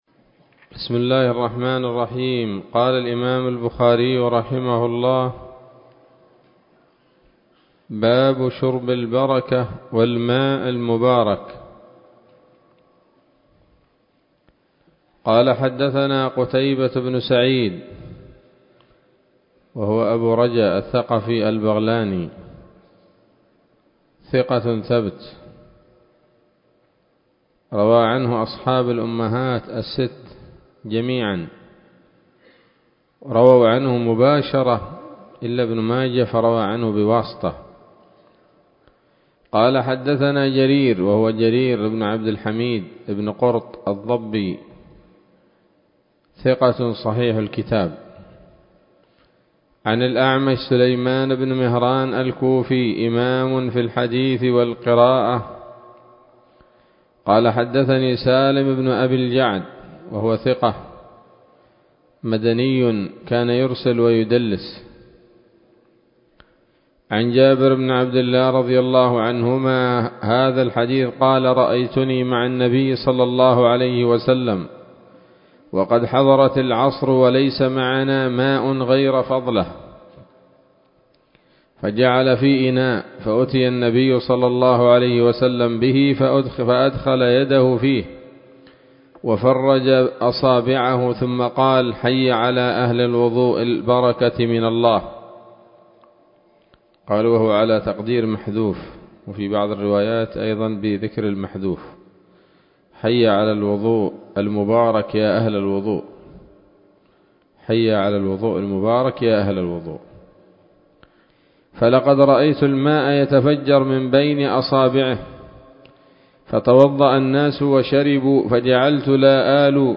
الدروس العلمية شروح الحديث صحيح الإمام البخاري كتاب الأشربة من صحيح البخاري
الدرس الثاني والعشرون وهو الأخير من كتاب الأشربة من صحيح الإمام البخاري